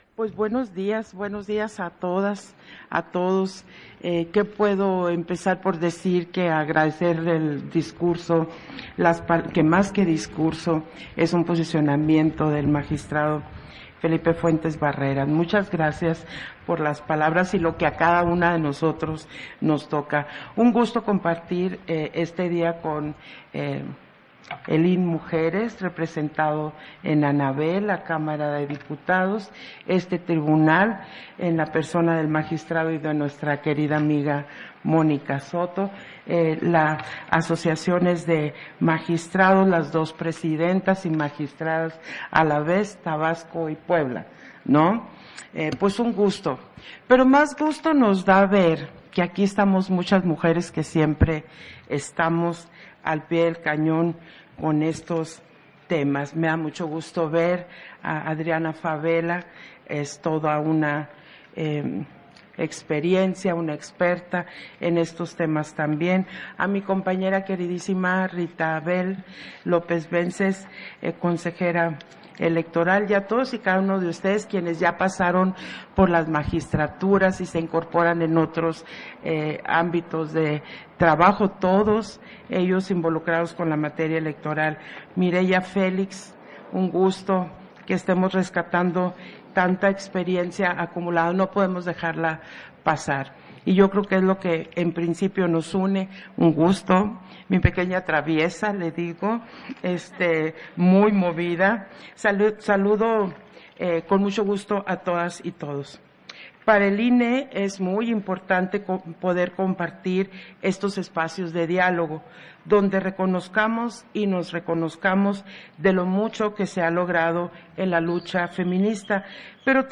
Audio del evento conmemorativo del día Internacional de las Mujeres
Intervención de Guadalupe Taddei, en el evento conmemorativo del día Internacional de las Mujeres